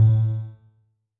chiptune
重做连击音效